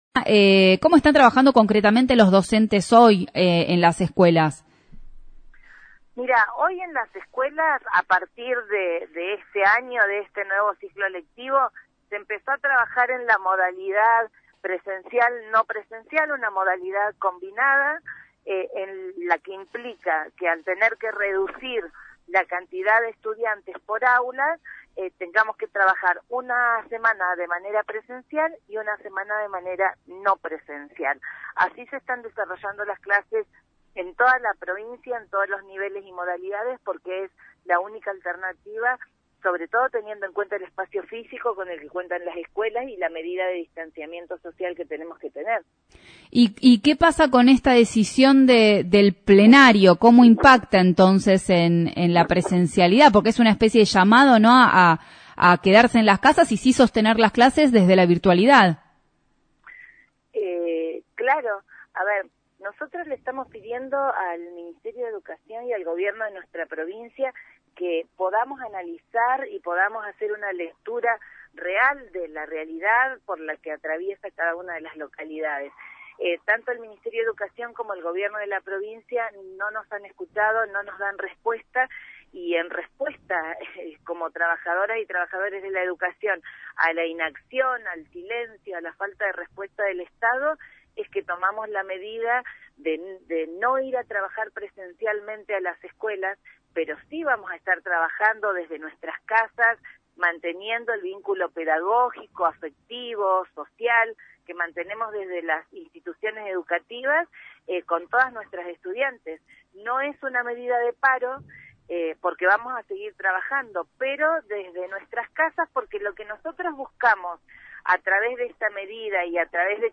Entrevistas realizadas por diferentes medios radiofónicos el 19/05/21.
Entrevista en Cadena 3, desde Bariloche: “Los dichos de la Ministra y del Viceministro de Educación son lamentables, consideran que no es nuestra atribución definir una no presencialidad y se olvidan de todo lo que no es nuestra obligación y también lo hacemos adentro de las escuela.